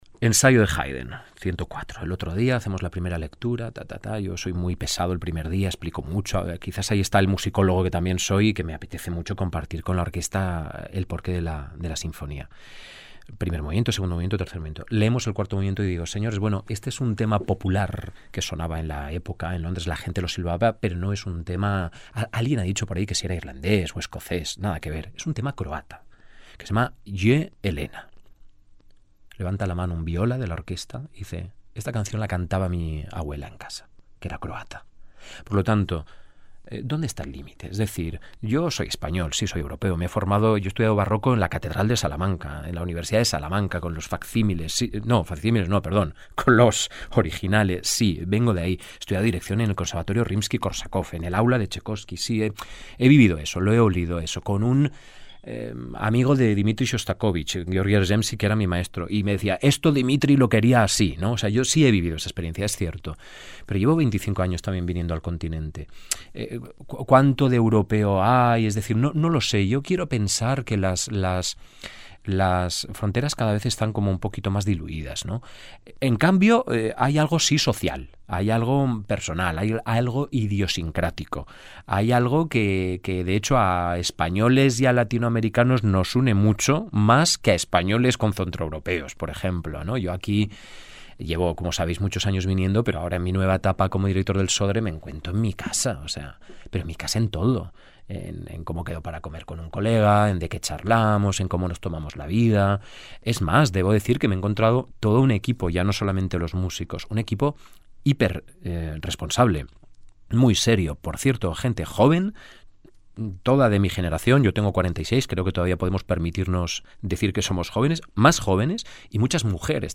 Compartimos la charla que mantuvimos a propósito del programa y su vínculo con Uruguay: